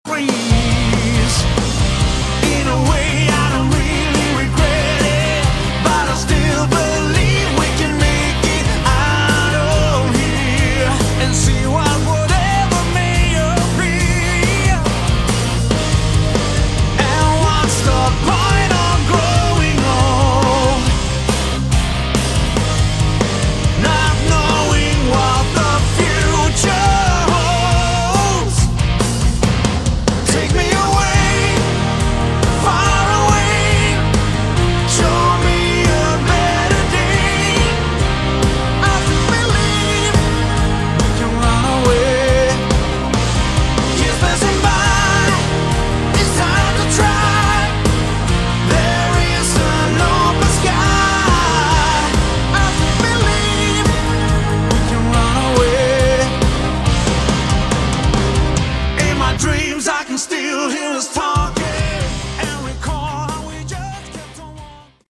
Category: Melodic Rock
lead vocals
guitar, bass, keyboards, backing vocals
drums, additional Keyboards, backing vocals